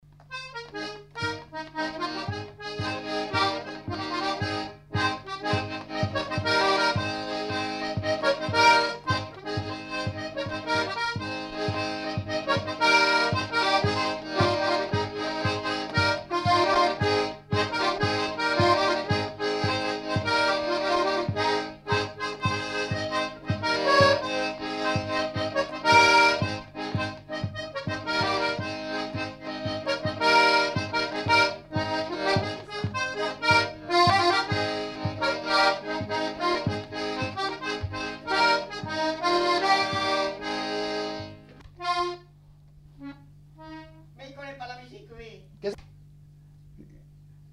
Aire culturelle : Savès
Genre : morceau instrumental
Instrument de musique : accordéon diatonique
Danse : rondeau